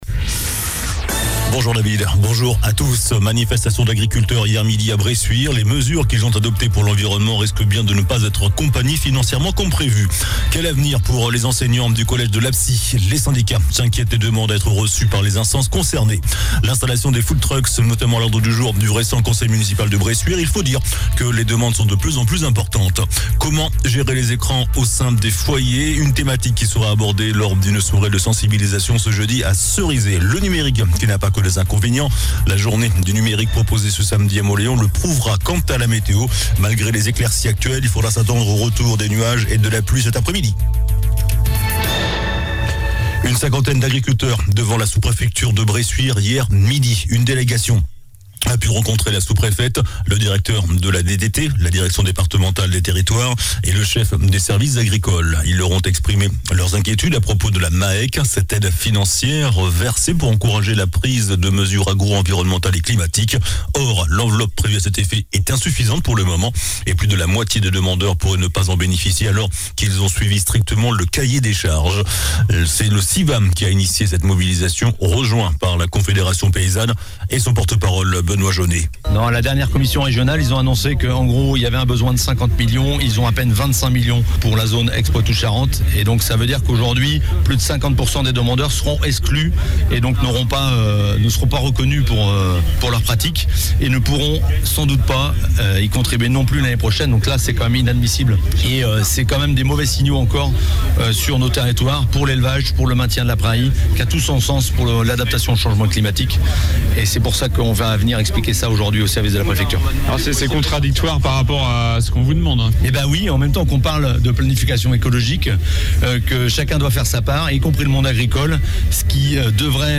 JOURNAL DU JEUDI 19 OCTOBRE ( MIDI )